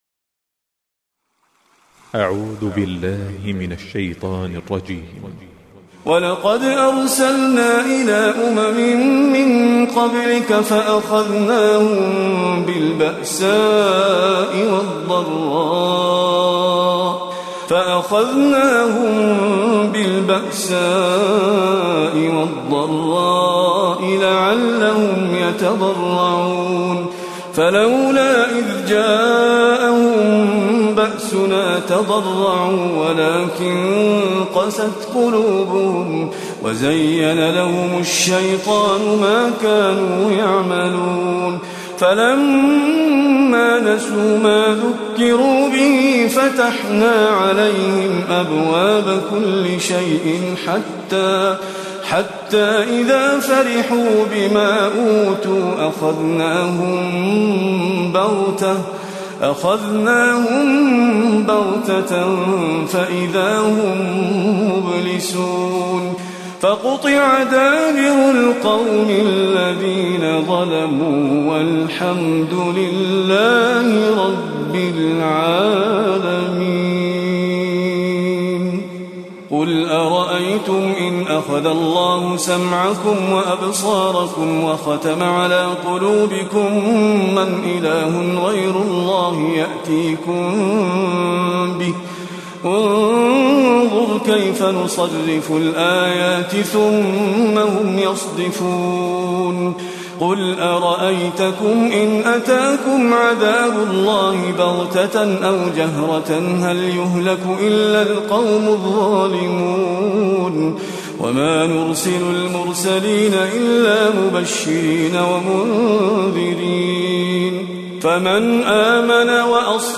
القارئ
الحرم النبوي ١٤٣٨هـ